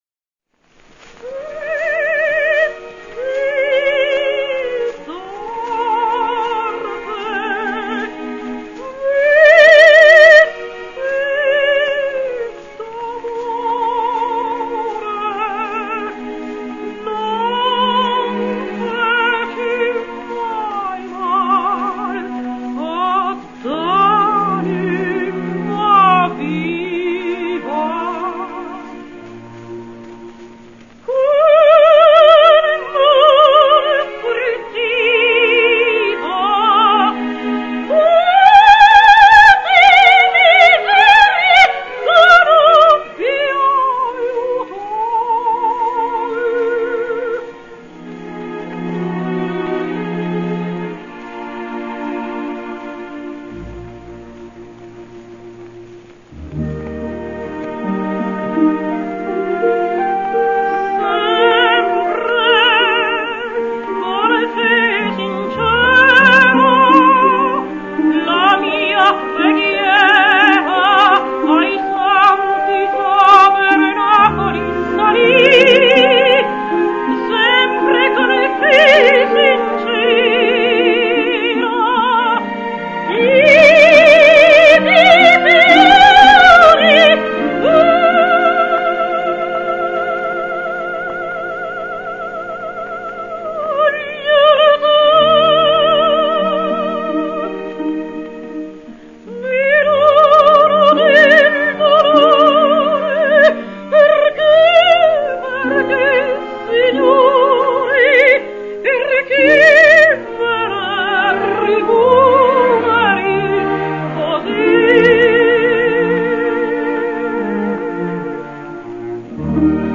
Rosetta Pampanini, like so many of her contemporaries, could not always resist the temptation to hoist the chest too high, but however, her singing had character with a variety of color and expression. She was one of the few sopranos of her generation who resisted the emphatic delivery including sobs and sighs.
The following file is one of Pampanini’s finest and most expressive achievements: